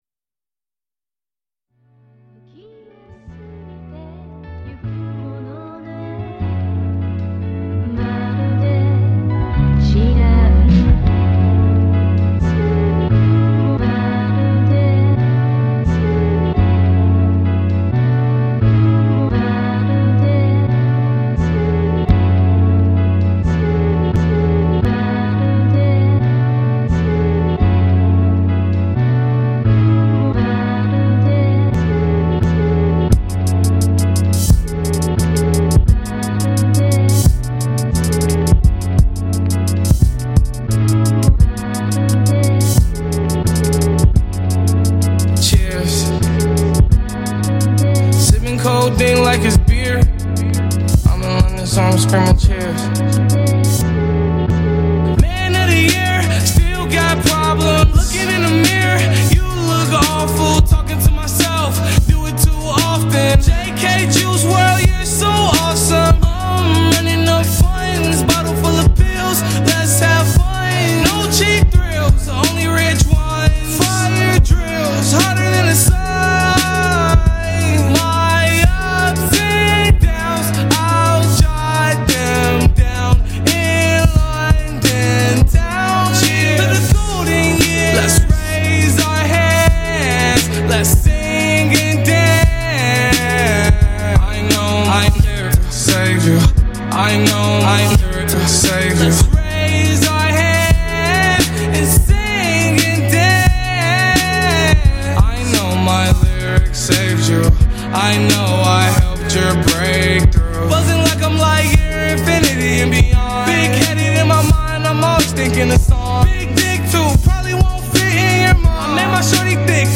87 BPM.
Music / Rap
upbeat
soul sample hip hop rap jazzy soulful jazz beat instrumental